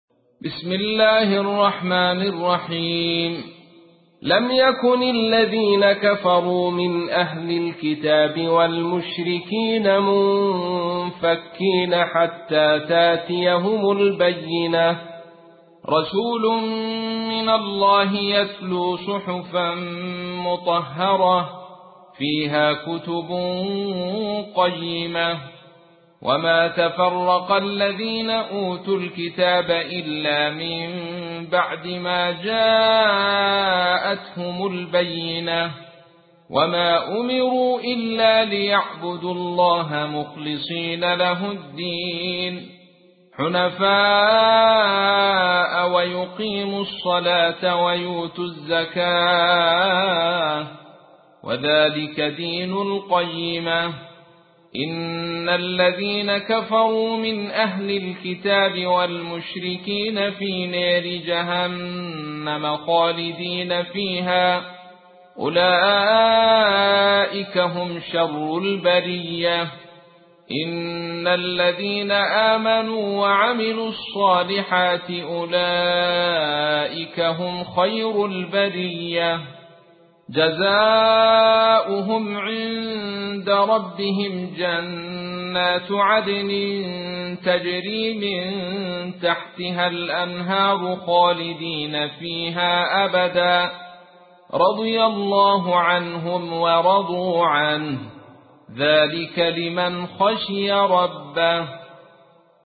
تحميل : 98. سورة البينة / القارئ عبد الرشيد صوفي / القرآن الكريم / موقع يا حسين